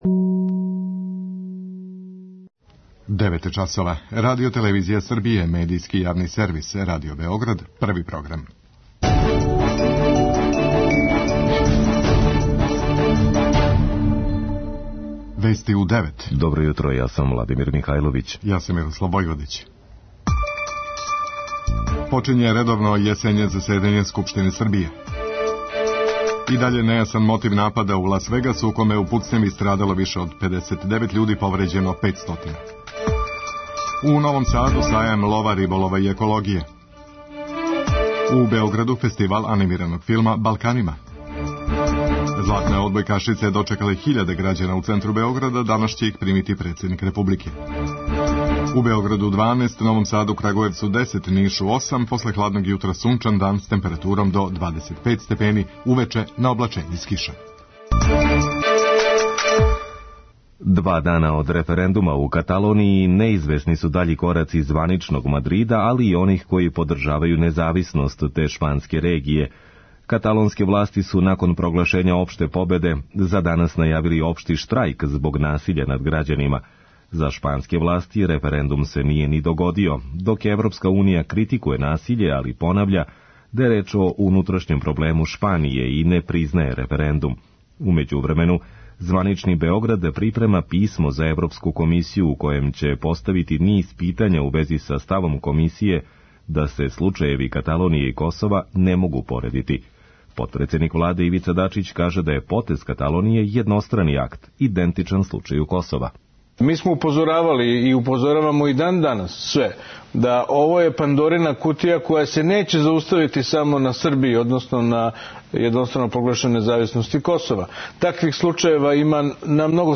преузми : 3.48 MB Вести у 9 Autor: разни аутори Преглед најважнијиx информација из земље из света.